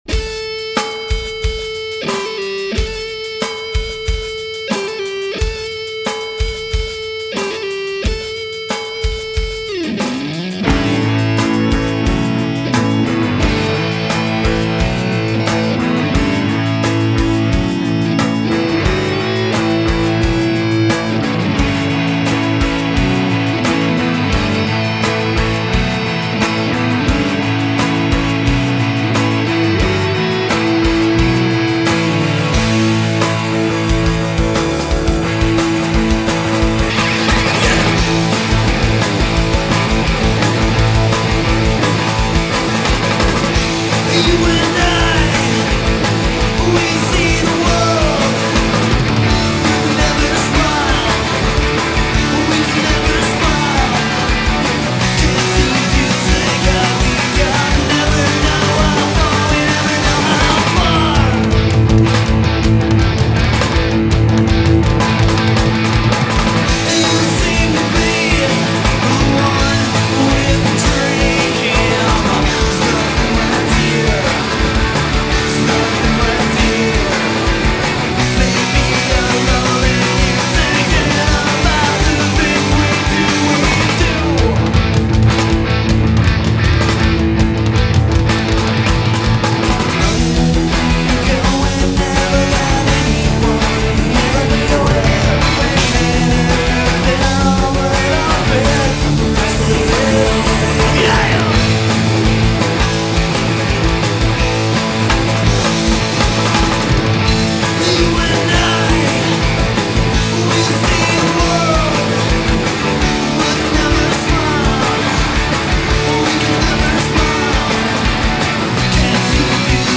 Vocals
Drums
Bass
Guitar
Emo , Indie